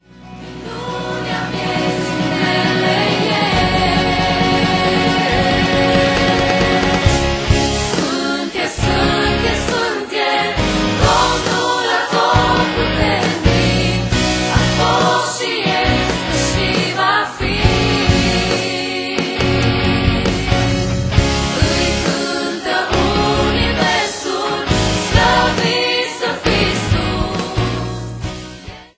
intr-un stil propriu si revigorant